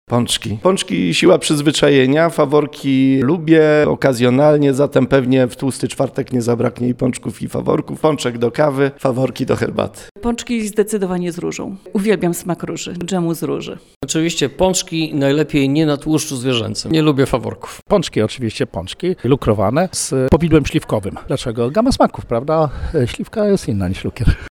sonda pączki
sonda-paczki.mp3